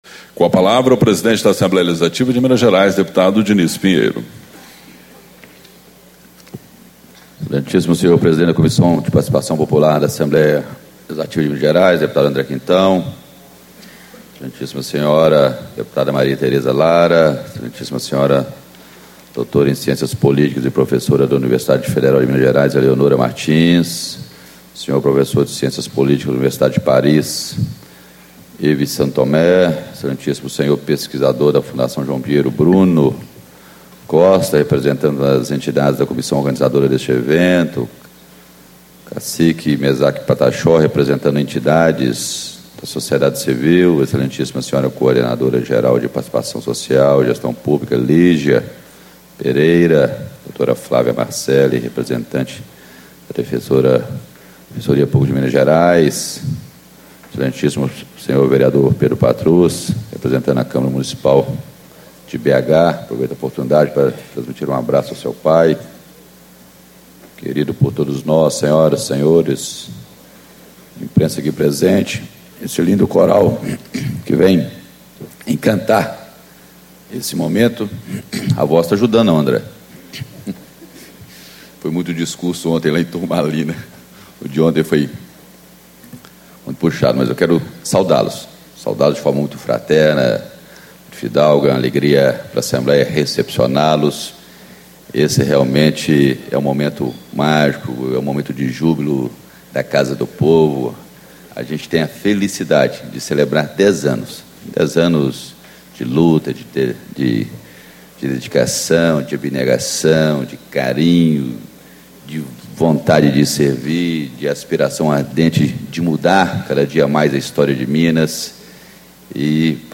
Abertura - Deputado Dinis Pinheiro, PSDB - Presidente da Assembleia Legislativa do Estado de Minas Gerais